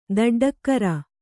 ♪ daḍḍakkara